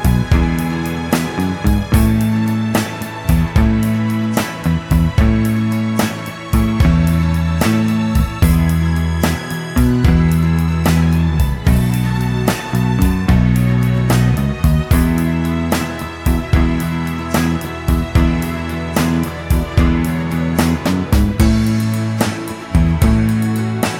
No Backing Vocals Crooners 3:04 Buy £1.50